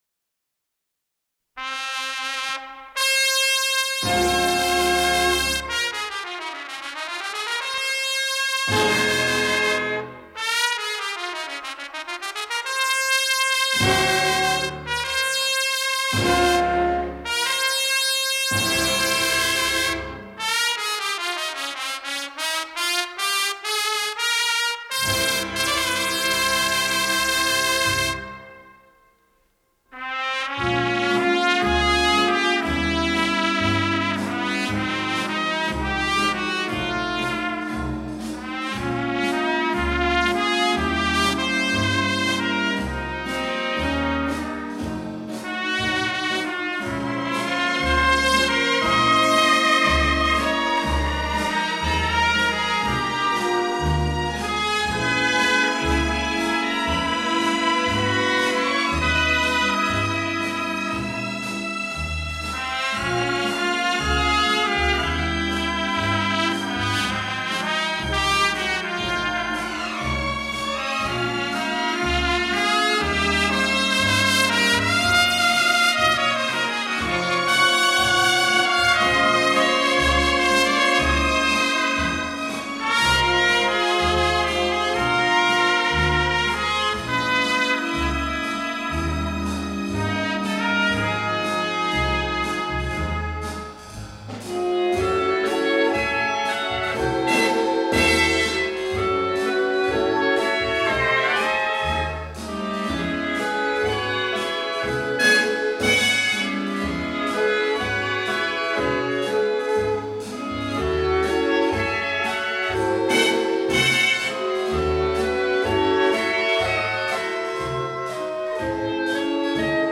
Ми бемоль мажор.